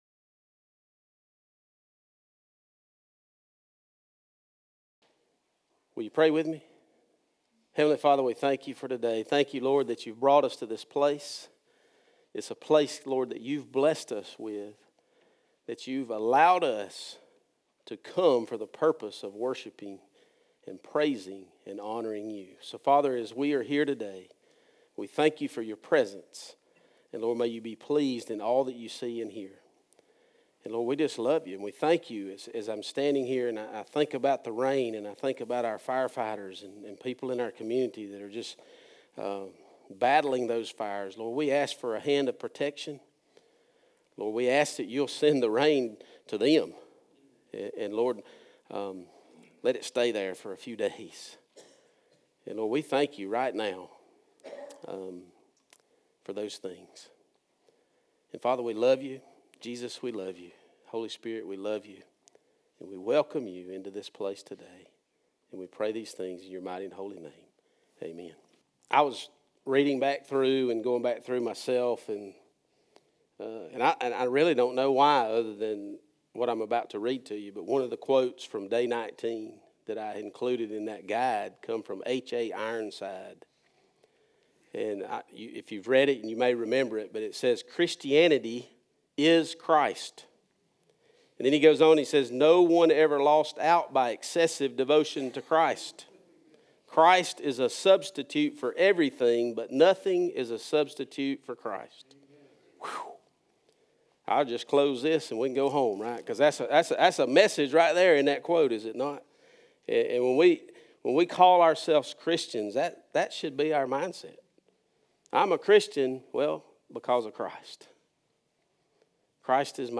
Message Type - Sermon
Occasion - Sunday Worship